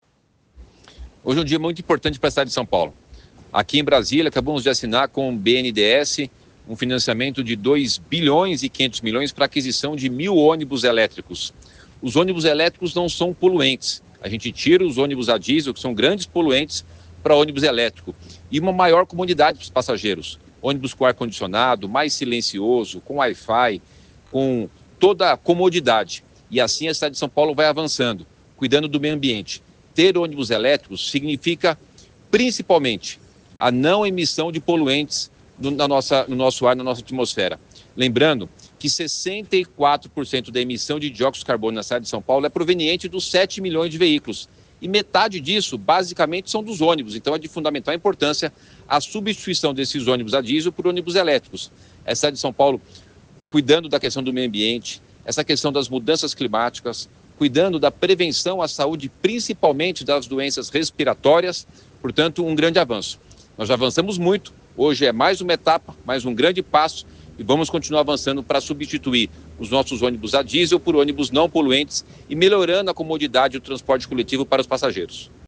Durante o evento, o prefeito Ricardo Nunes falou sobre a importância da eletrificação da frota, exaltando os benefícios ambientais do processo.
Confira declaração de Ricardo Nunes: